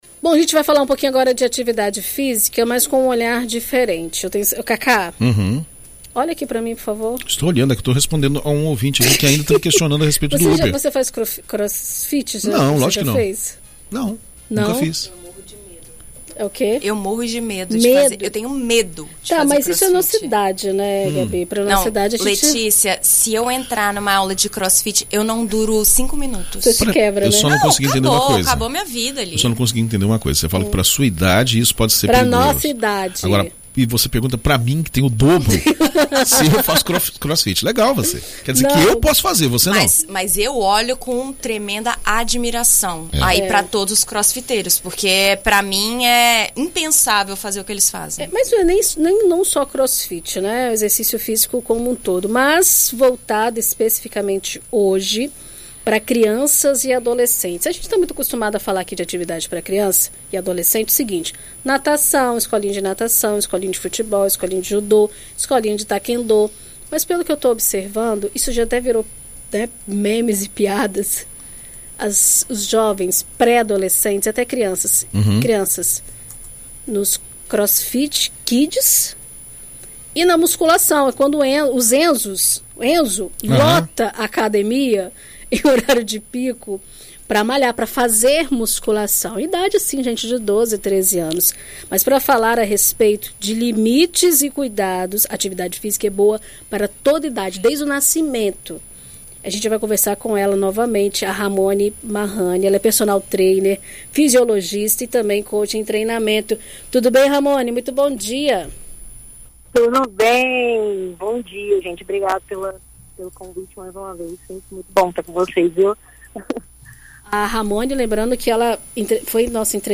Em entrevista à BandNews FM Espírito Santo nesta terça-feira